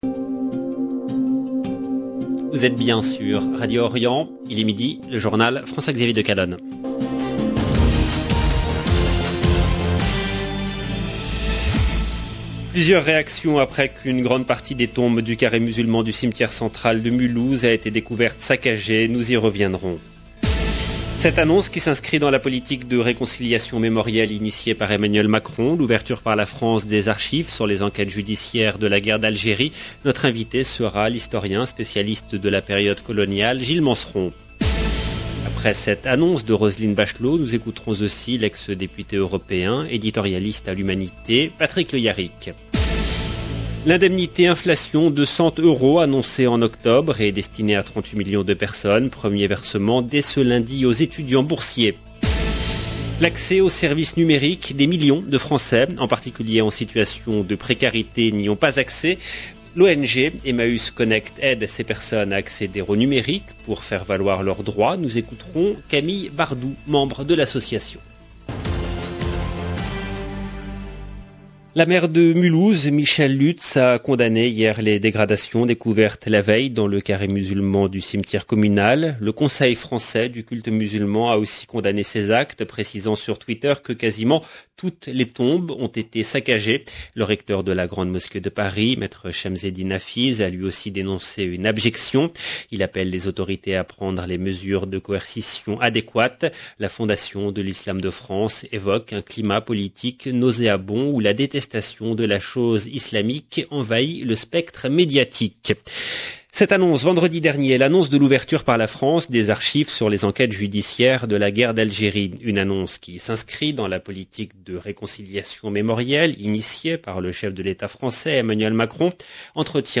LE JOURNAL EN LANGUE FRANCAISE DE MIDI DU 13/12/21